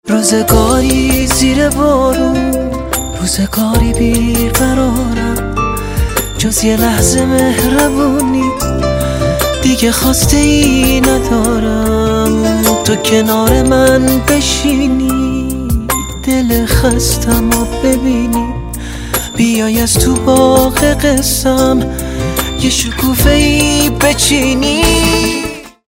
رینگتون آرام و باکلام